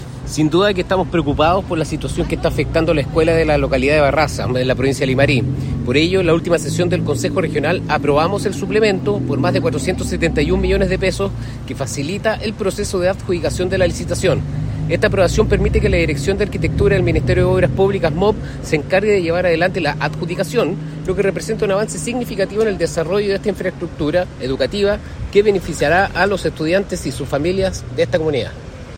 Como detalló el gobernador Cristóbal Juliá,
Gobernador-Cristobal-Julia.mp3